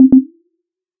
Notifications 🔔